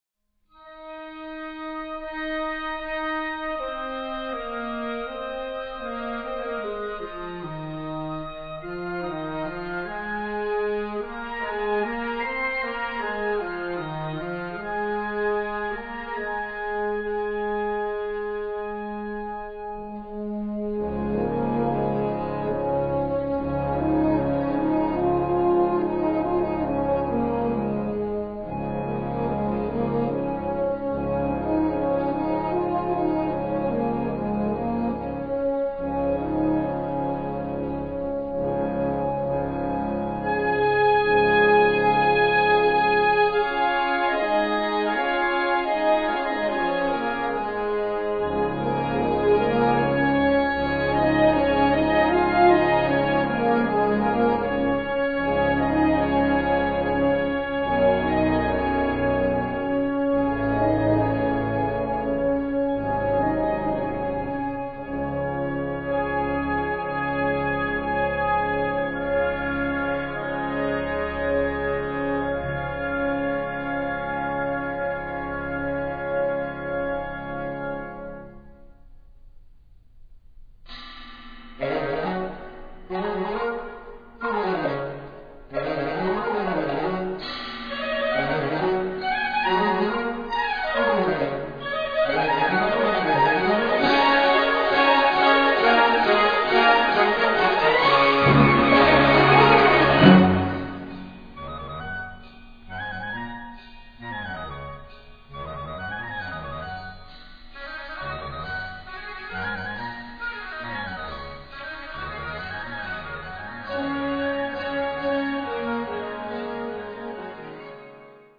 Gattung: Zeitgenössische Originalmusik
Besetzung: Blasorchester